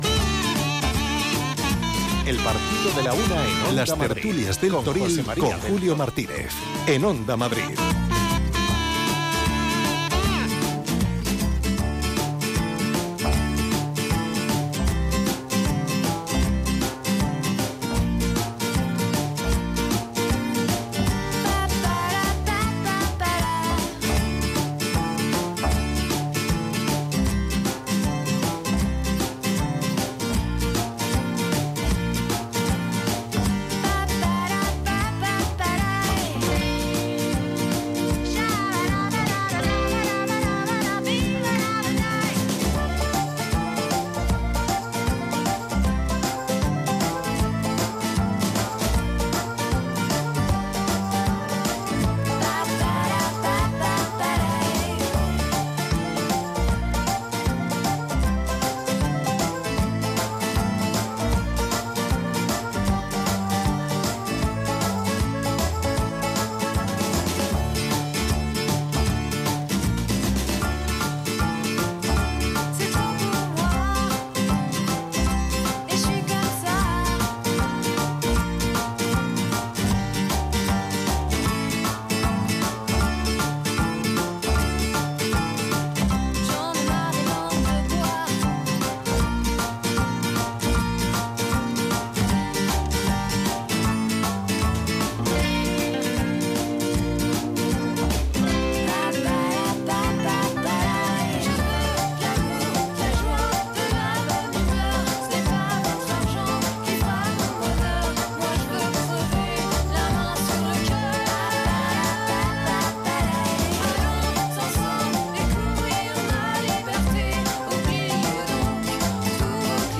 Con la Feria de San Isidro, vuelven las 'Las Tertulias del Toril' a Onda Madrid.